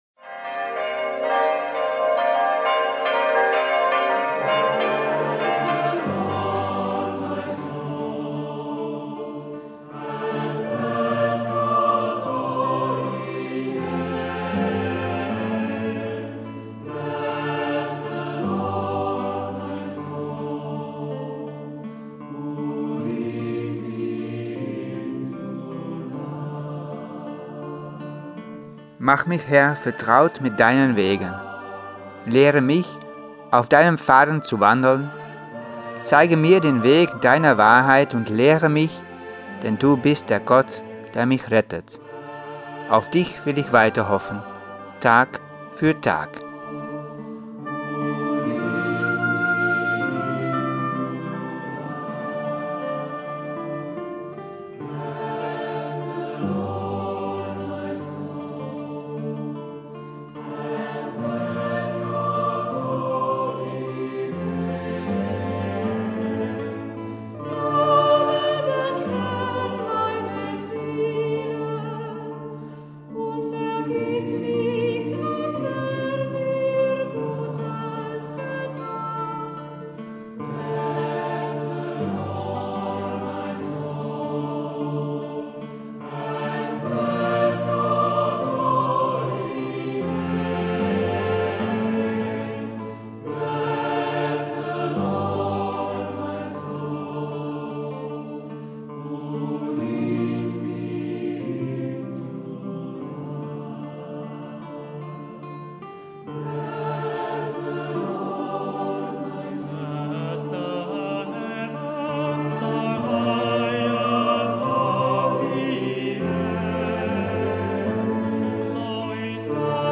Meditationspodcast für den 1. Fasten Sonntag